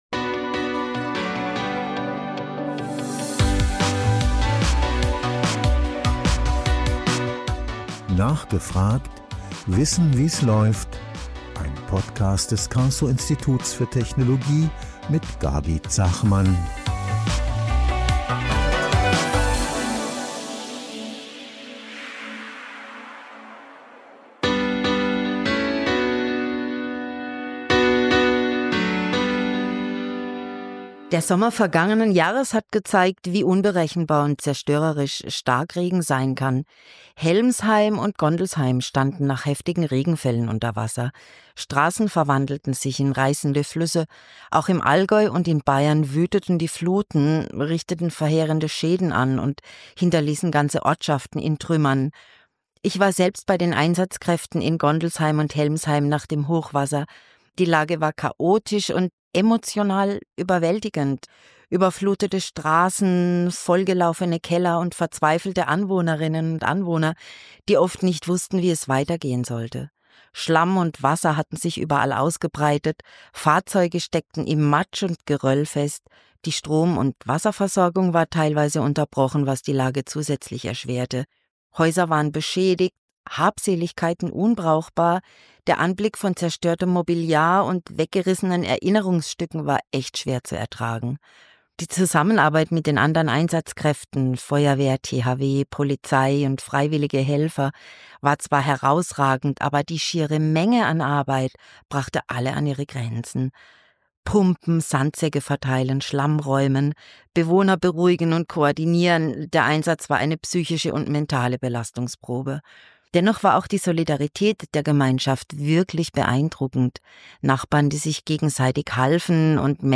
Im Interview-Podcast des Karlsruher Instituts für Technologie (KIT) sprechen unsere Moderatorinnen und Moderatoren mit jungen Forschenden, die für ihr Thema brennen.